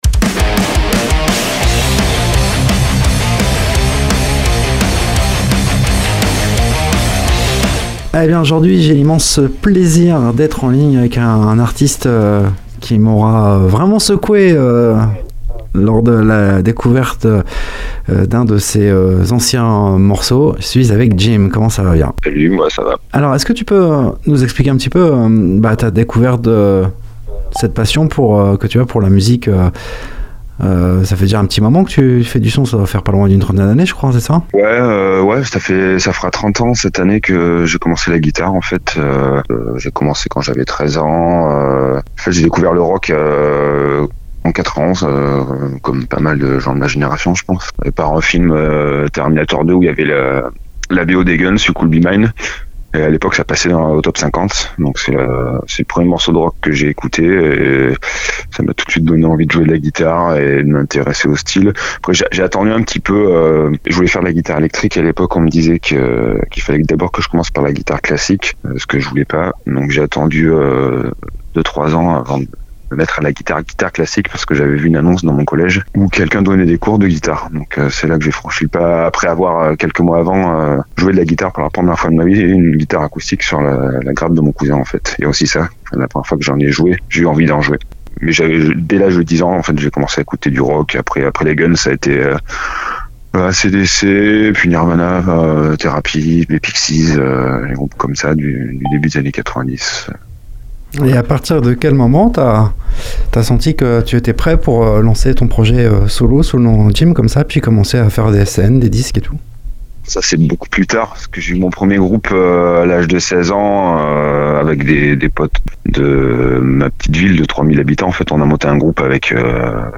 Riffs de ouf, jeux de guitare dignes des plus grands
Il nous en parle et nous vous en faisons découvrir un extrait. metal rock